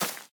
Minecraft Version Minecraft Version 1.21.5 Latest Release | Latest Snapshot 1.21.5 / assets / minecraft / sounds / block / cave_vines / break5.ogg Compare With Compare With Latest Release | Latest Snapshot
break5.ogg